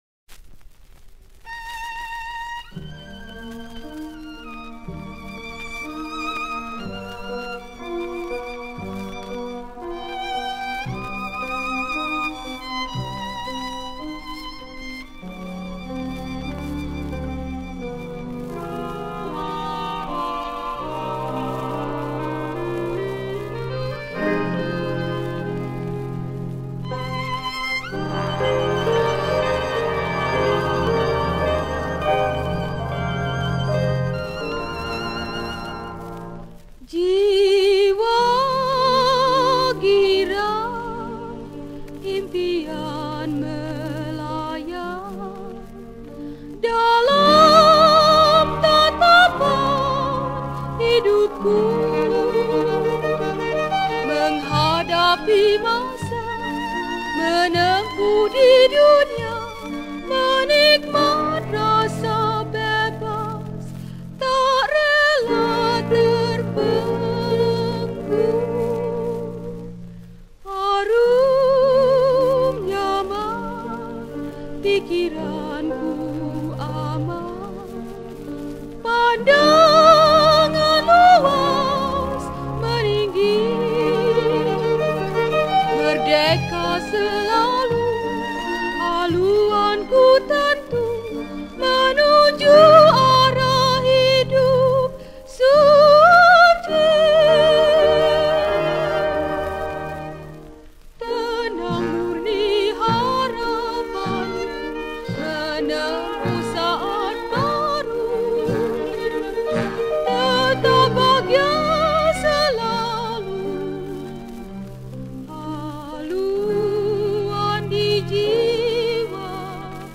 Malay Songs , Patriotic Songs